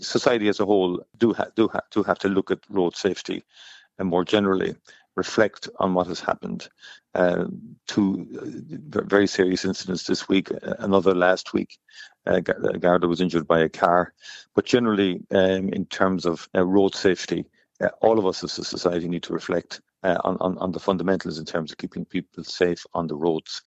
The Taoiseach says it’s time for the nation to reflect on road safety: